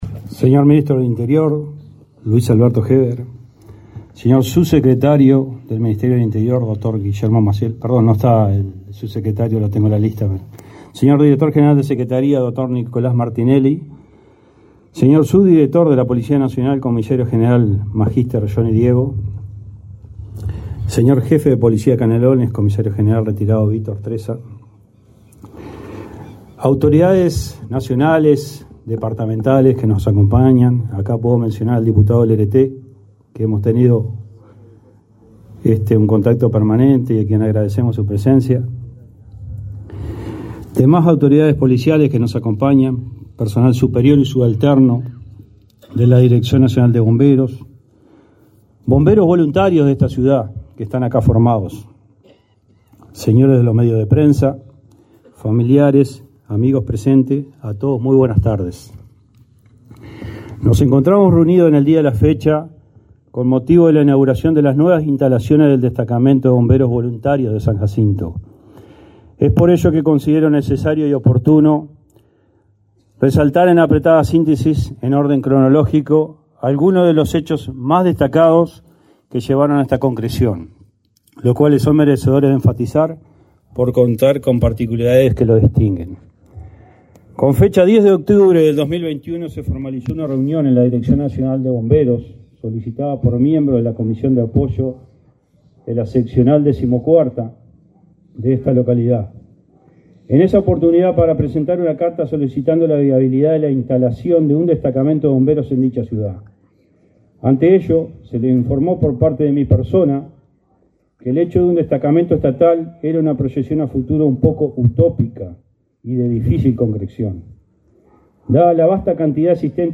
Acto de inauguración de destacamento de bomberos en San Jacinto
Acto de inauguración de destacamento de bomberos en San Jacinto 28/07/2023 Compartir Facebook X Copiar enlace WhatsApp LinkedIn El Ministerio de Interior inauguró, este 28 de julio, un destacamento de bomberos compuesto por profesionales y voluntarios, en la localidad de San Jacinto, en Canelones. Participaron en el evento el titular de la cartera, Luis Alberto Heber, y el director nacional de Bomberos, Jorge Riaño.